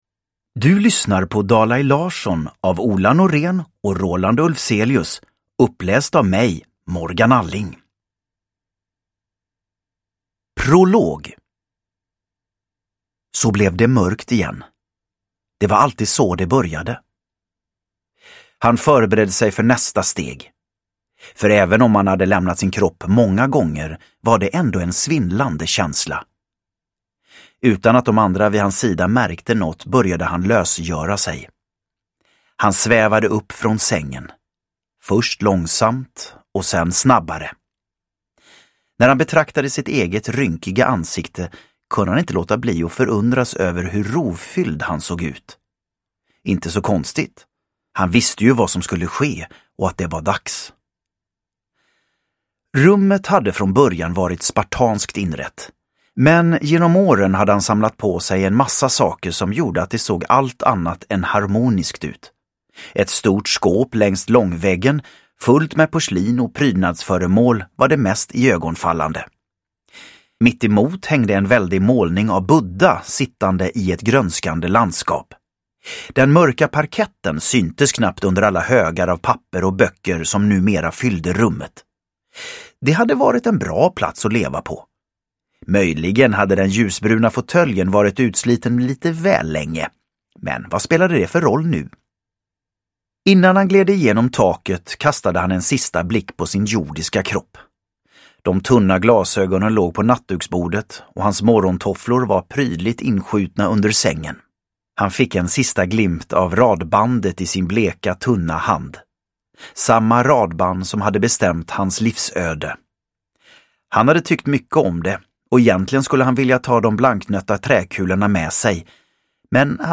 Dalai Larsson – Ljudbok
Uppläsare: Morgan Alling